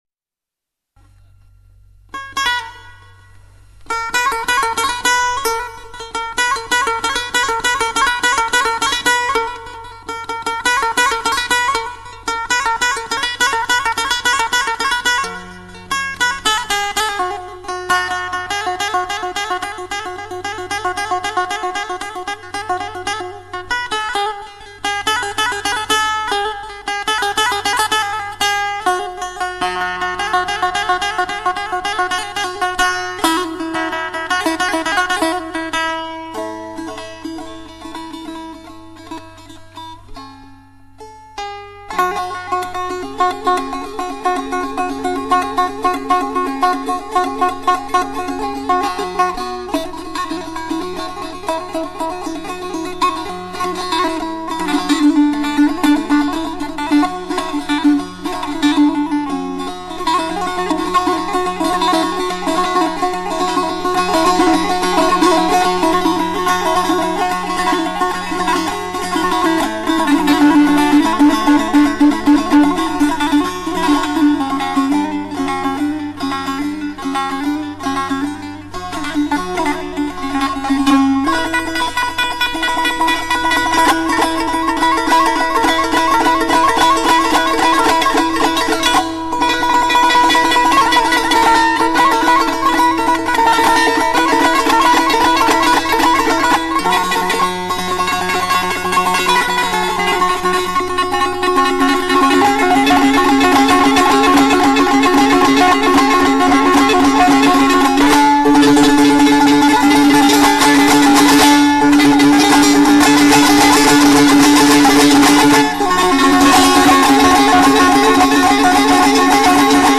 تک نوازی بسیار زیبای ساز دلنشین دوتار